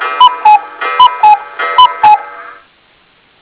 cuckoo.wav